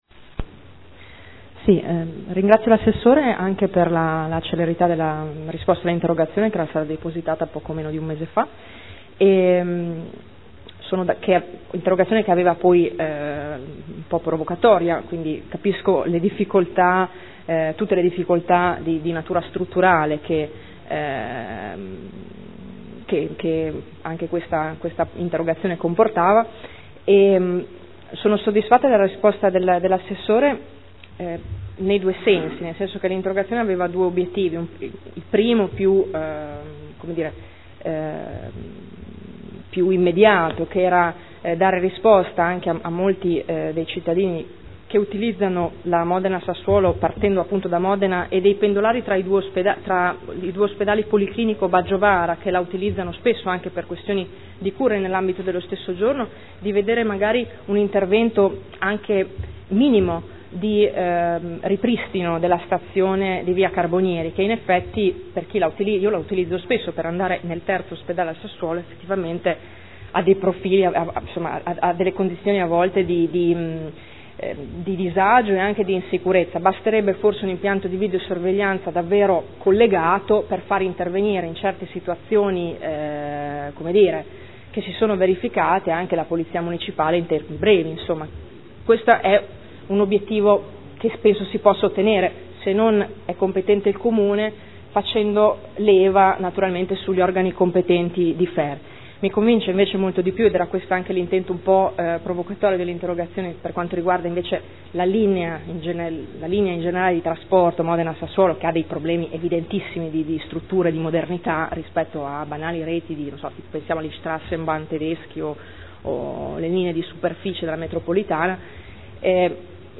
Giuliana Urbelli — Sito Audio Consiglio Comunale